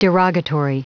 Prononciation du mot : derogatory